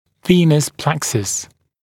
[‘viːnəs ˈpleksəs][‘ви:нэс ˈплэксэс]венозное сплетение